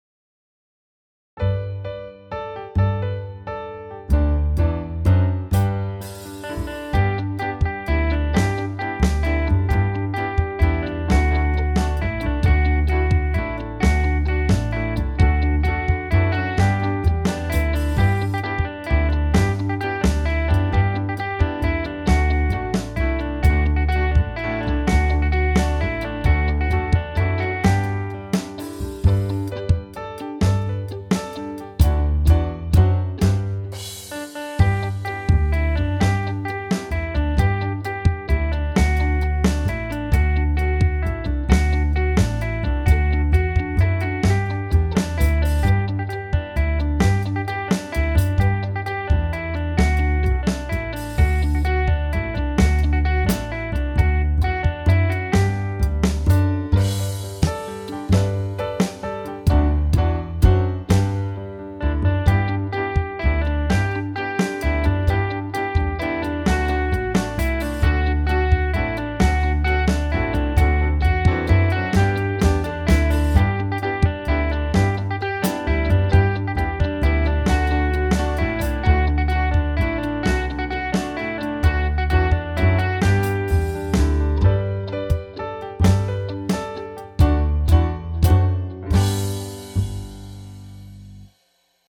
Musik und Text: Trad.
Playbackversion